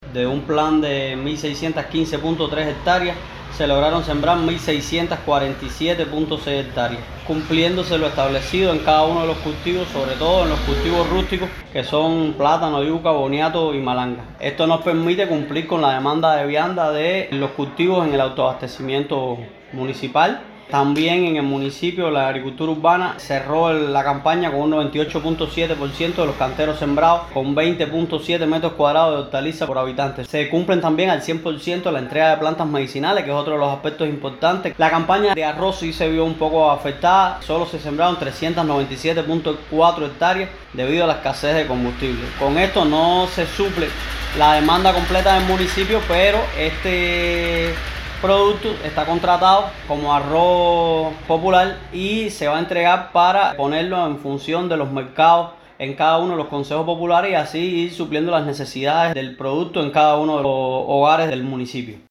Al respecto conversamos con Oslay Díaz Pagés, viceintendente de Alimentos del poblado, quien reconoció el compromiso de la máxima dirección territorial con el desarrollo agropecuario y el apoyo a los productores betancourenses, al tiempo que destacó los logros alcanzados en la campaña de primavera previa.